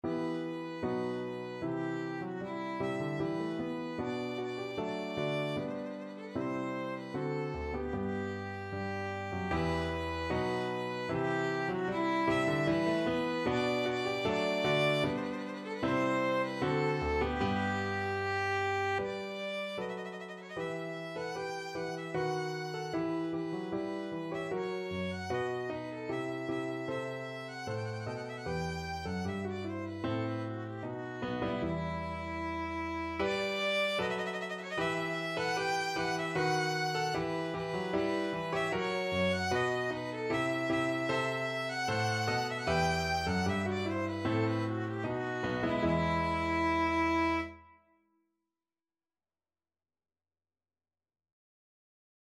Violin
2/2 (View more 2/2 Music)
E5-G6
E minor (Sounding Pitch) (View more E minor Music for Violin )
Steadily =c.76
Classical (View more Classical Violin Music)